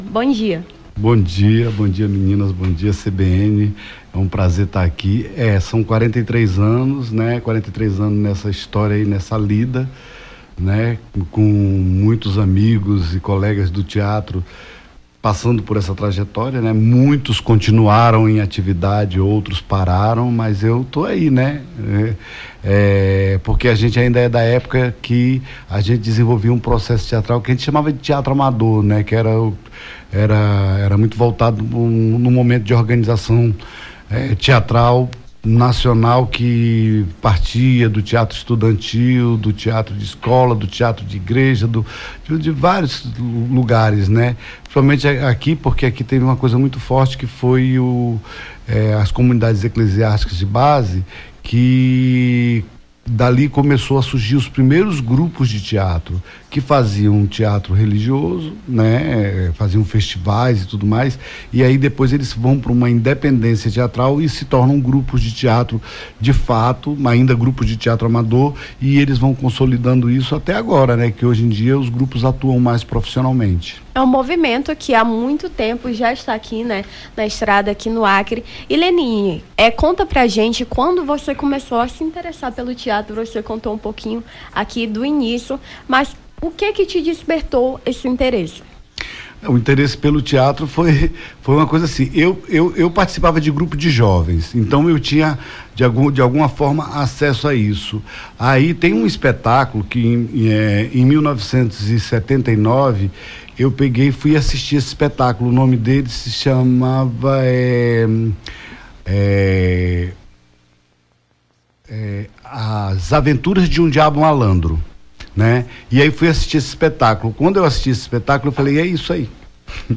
Nome do Artista - CENSURA - ENTREVISTA (DIA DO TEATRO) 19-09-23.mp3